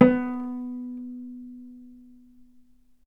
vc_pz-B3-ff.AIF